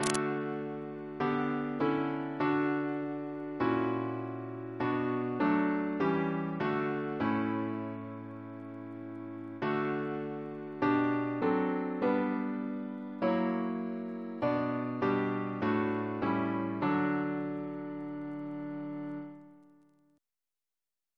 Double chant in D Composer: William Taylor (b.1878) Reference psalters: OCB: 129; PP/SNCB: 232